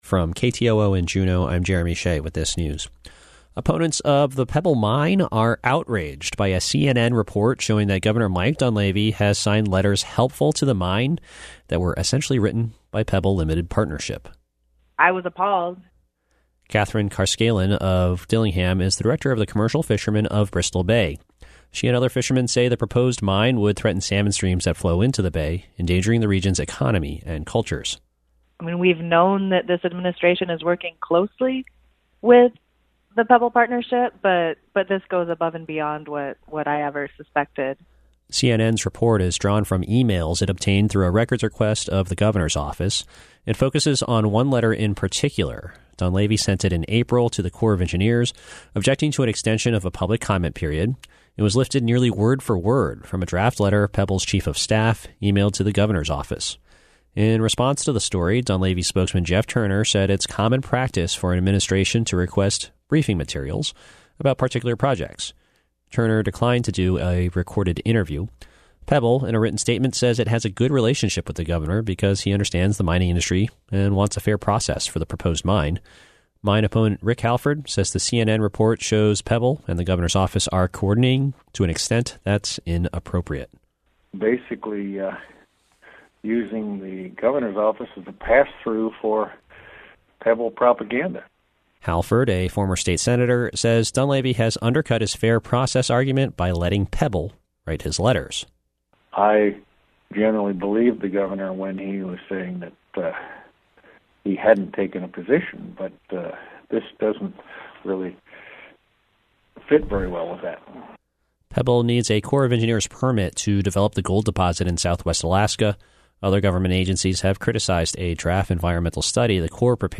Newscast – Friday, Dec. 20, 2019